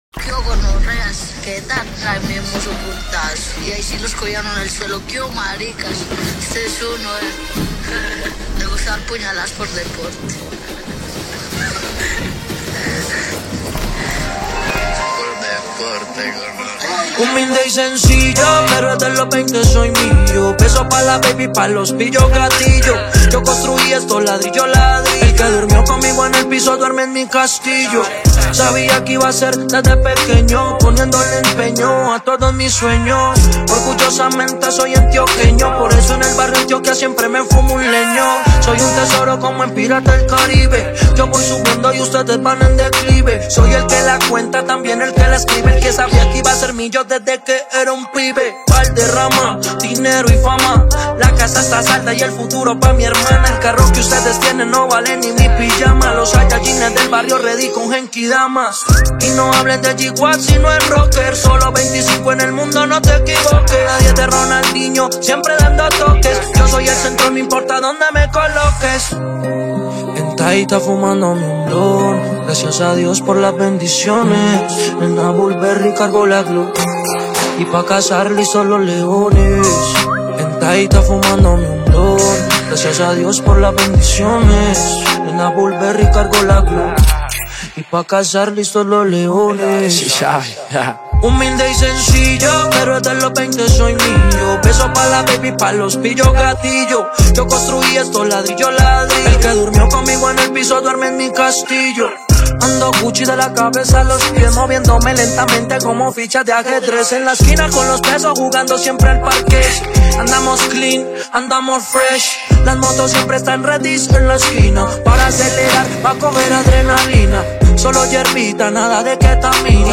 género urbano